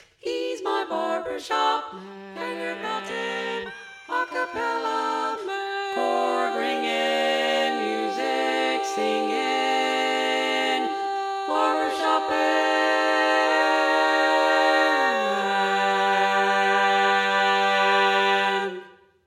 Key written in: G Major
How many parts: 4
Type: Female Barbershop (incl. SAI, HI, etc)
All Parts mix: